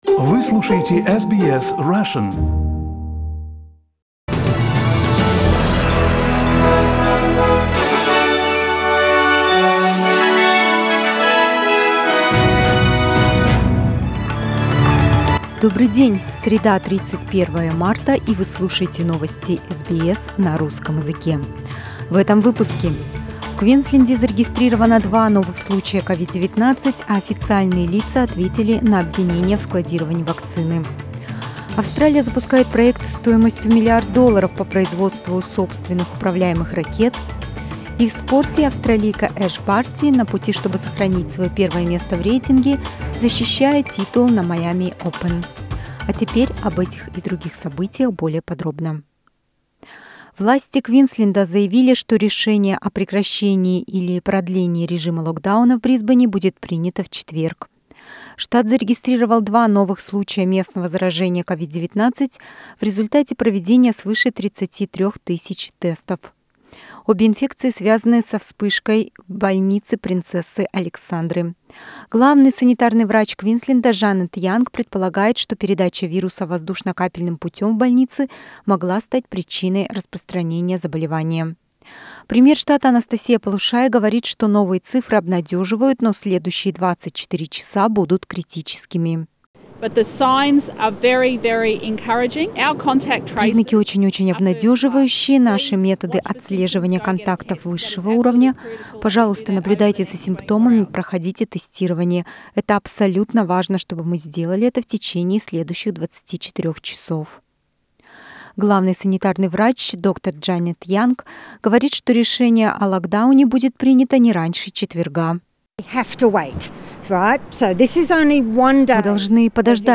News bulletin in Russian, March 31